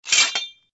Converted sound effects
TL_rake_pickup_only.ogg